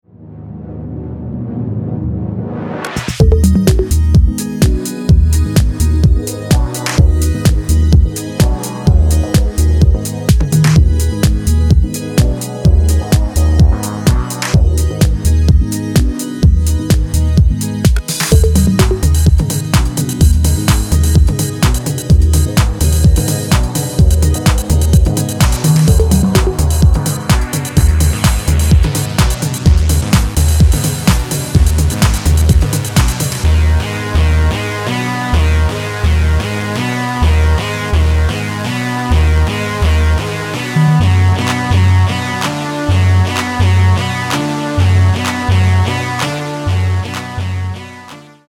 moody, mellow, but freaked out “Spaceship” Electrosongs
acid lines, disco breaks, rave synthesizers, drum machines
sleazy chilled out music